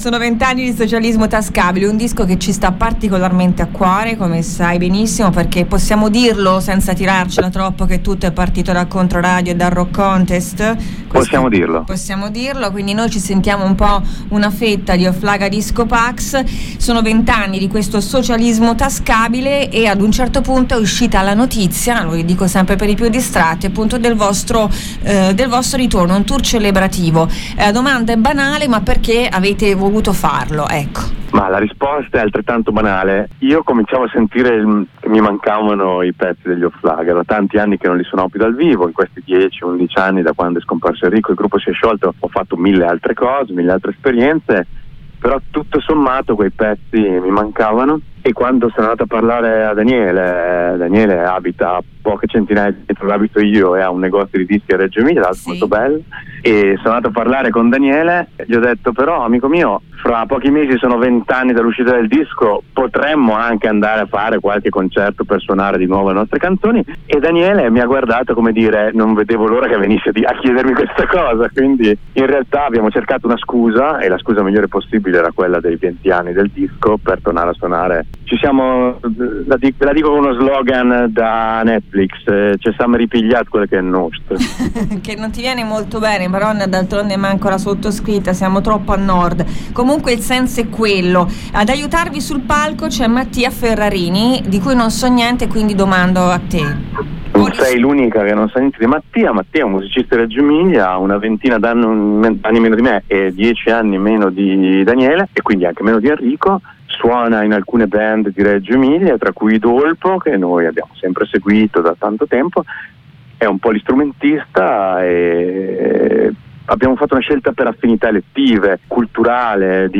Offlaga Disco Pax: l'intervista